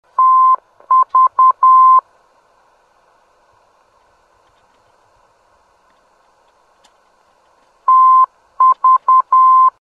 TV - Traverse City heard on 365 kHz: (68kb)